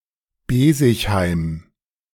Besigheim (German pronunciation: [ˈbeːzɪçˌhaɪm]
De-Besigheim.ogg.mp3